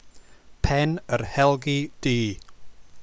Pen yr Helgi Du is also in Snowdonia. “Helgi Du” means black hound. To hear how to pronounce Pen yr Helgi Du press play: